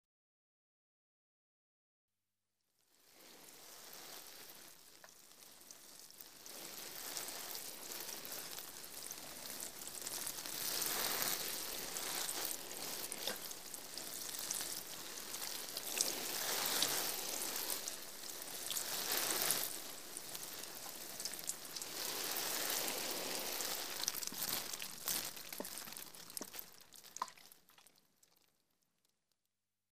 Звуки лейки
Шум лейки при поливе растений в загородном доме